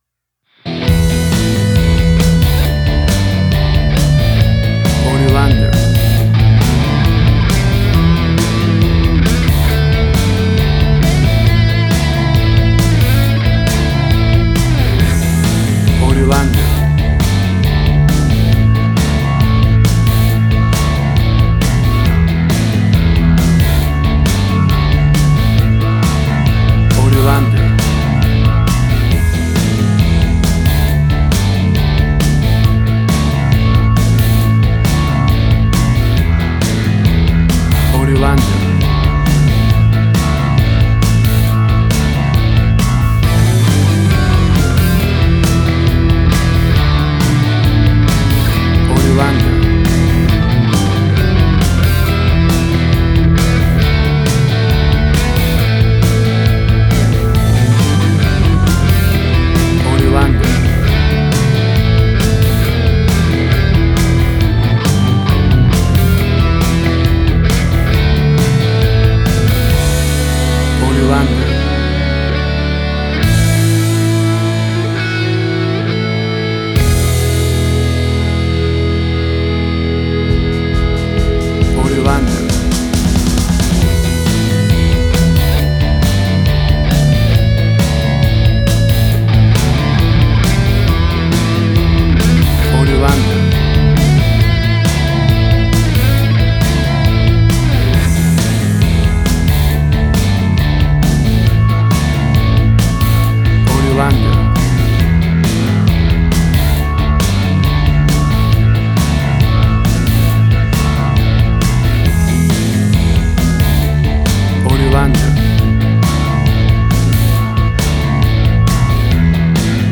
WAV Sample Rate: 16-Bit stereo, 44.1 kHz
Tempo (BPM): 135